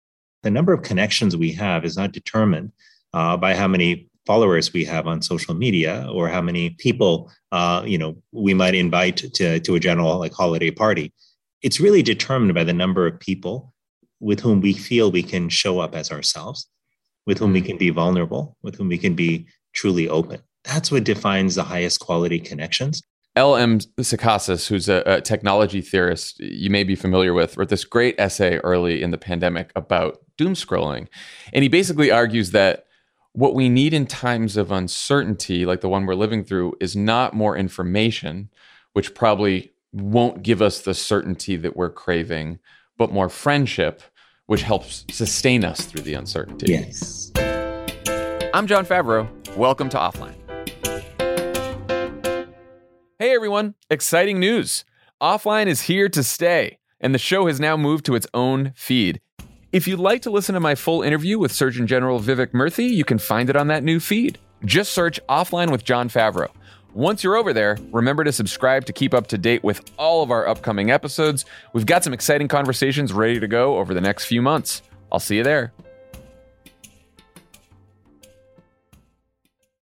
Surgeon General Dr. Vivek Murthy joins Jon on Offline to dissect the intersection between the internet and our emotional well-being. Dr. Murthy delivers a doctor’s diagnosis on Jon’s ceaseless doomscrolling, breaks down the impacts the pandemic and our increasing time online have had on our mental health, and makes the case for what it means to live a truly meaningful, connected life.